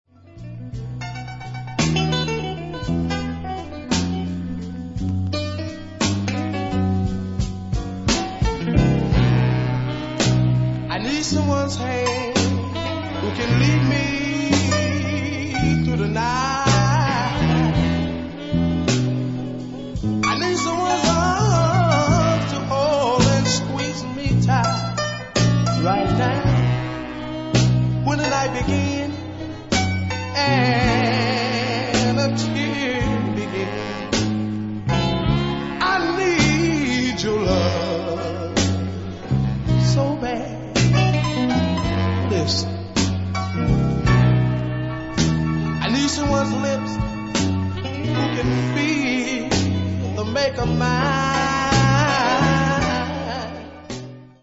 Very good quality Deep Soul!
Deep Soul